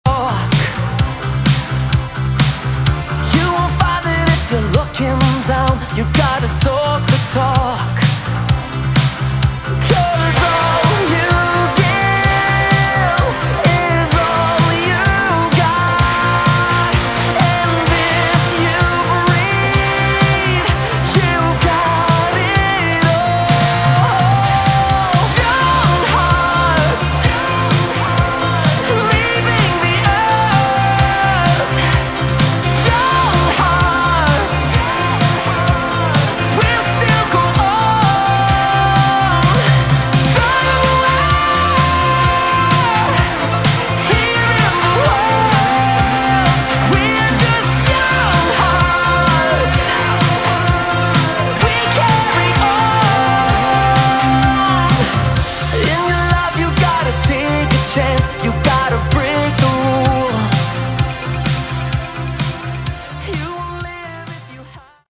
Category: Melodic Rock / AOR
guitar, vocals
keyboards, vocals
bass